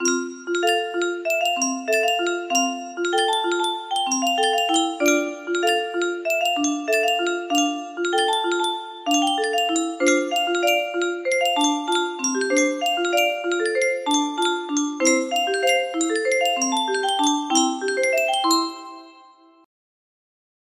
Stanza & Chorus parts - Short